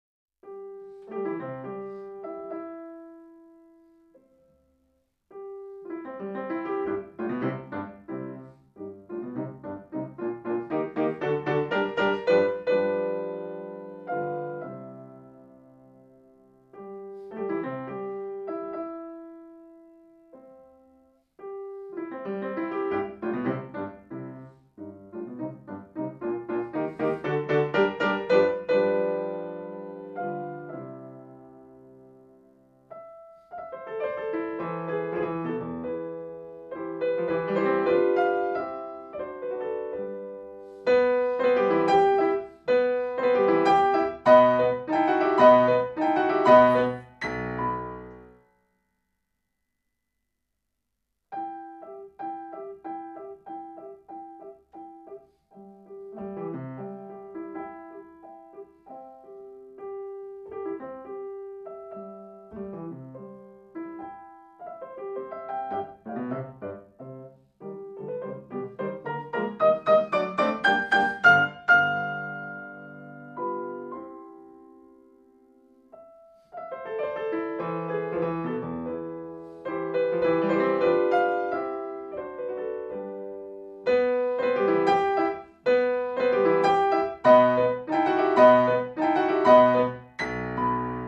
piano
1  C-dur Moderato.mp3